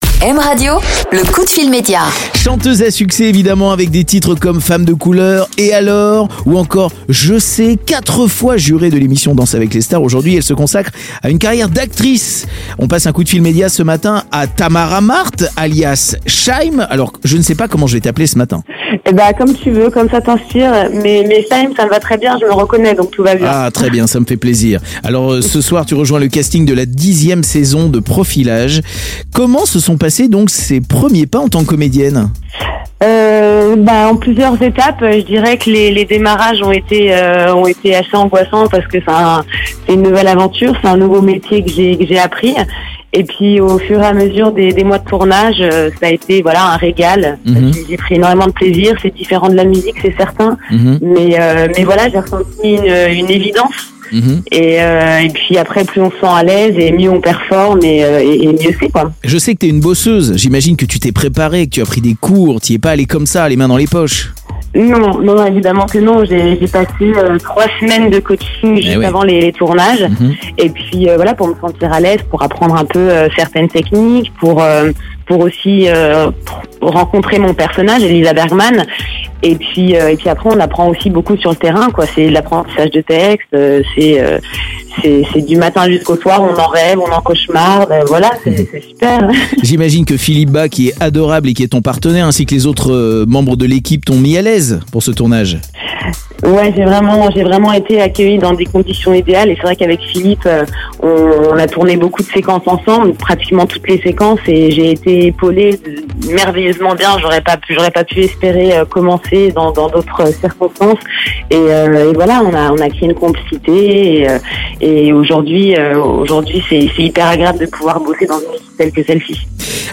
Podcast : Le coup de fil média 12 Mars 2020 Le coup de fil média avec Tamara Marthe (Shy'm) Jeudi 12 mars, Jérôme Anthony passe un coup de fil à Tamara Marthe, alias Shy'm, pour nous parler de la série Profilage ce soir sur TF1.